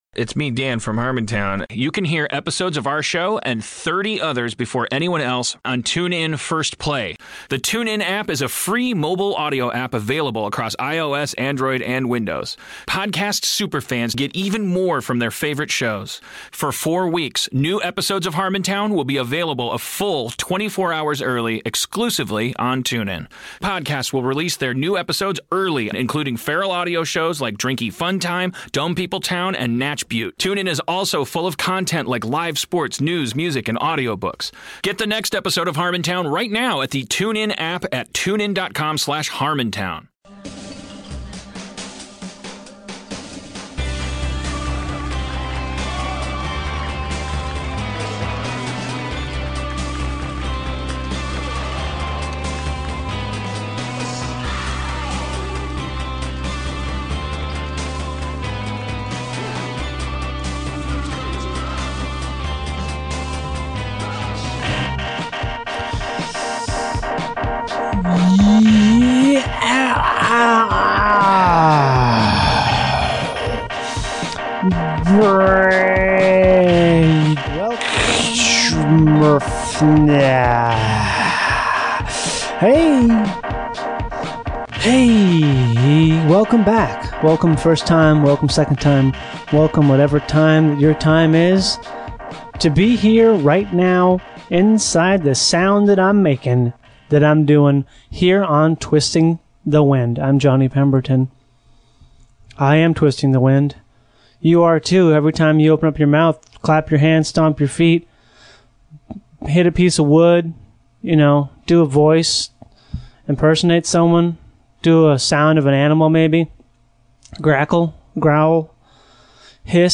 Johnny sits down with musician, actor, and elder hepcat Lee Weaver for regalement in legendary tales of yesteryear. Flanking the interview are a diverse trio of phone calls in which the following topics are dealt with through forced conversation: sloths, flute solo, Yanni, rest, bullet speed.